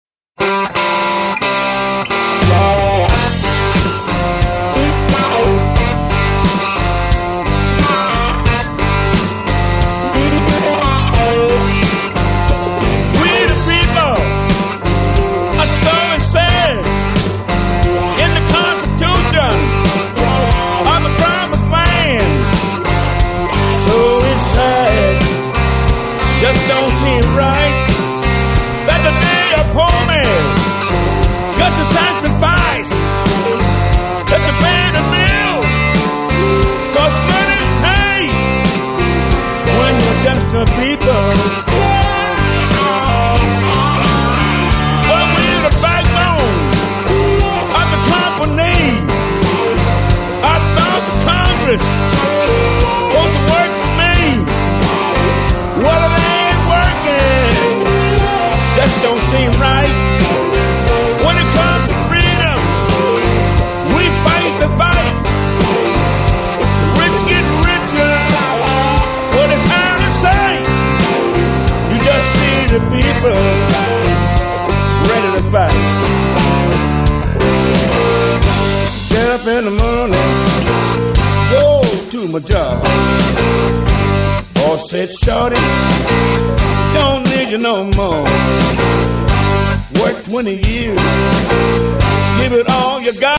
藍調音樂